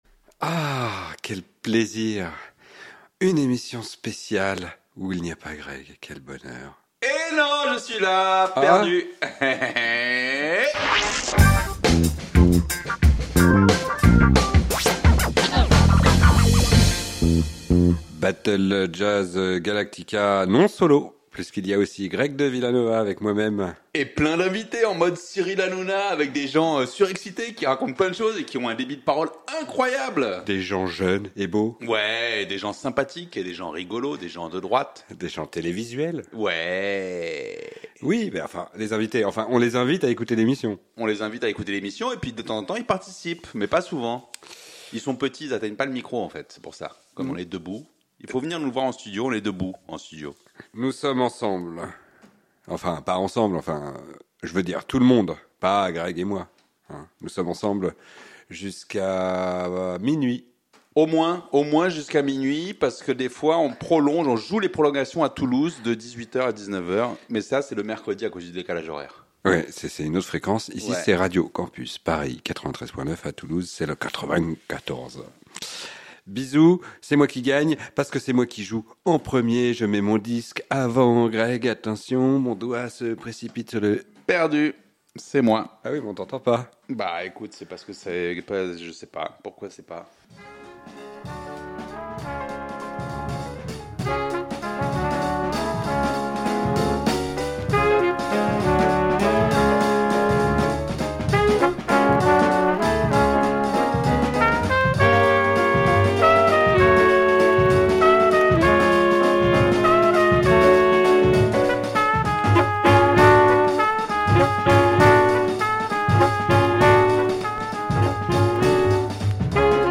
Ouais, parce qu'on parle aussi le anglais dans la émission.
Jazz and Big Words Tonight !
Classique & jazz